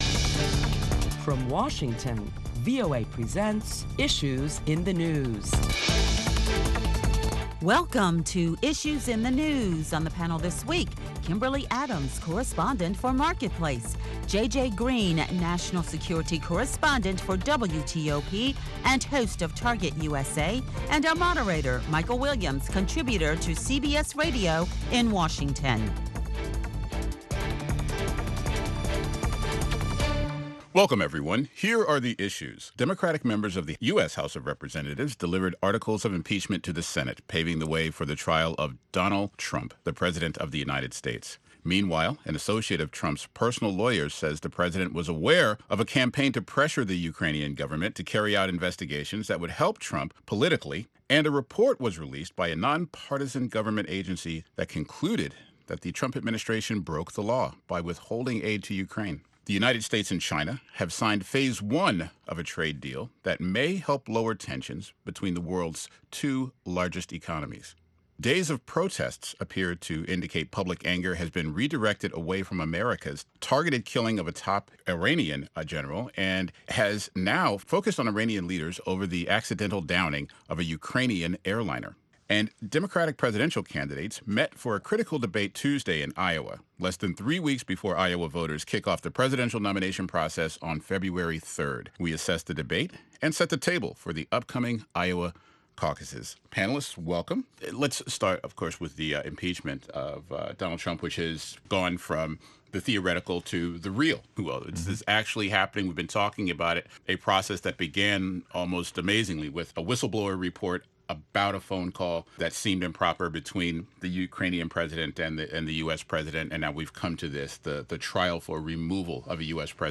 Listen to a panel of prominent Washington journalists as they deliberate the latest top stories of the week that include the U.S. looking ahead to the next phase of the trade agreement with China.